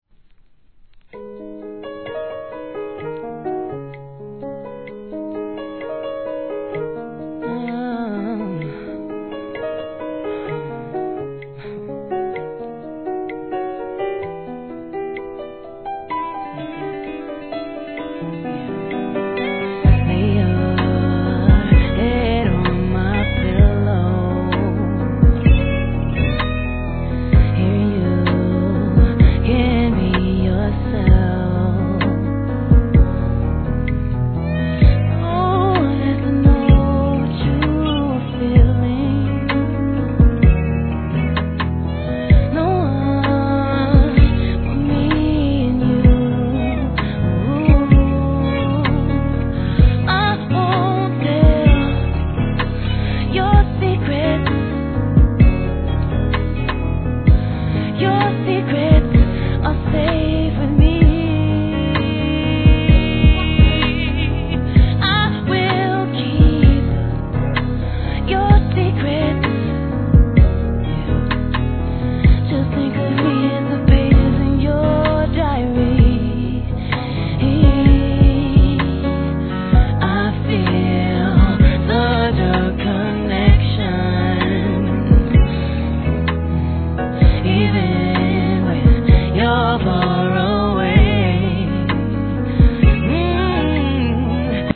HIP HOP/R&B
でもしっとりとしたバラードを基調とした切ないピアノに、天性のヴォーカルを披露。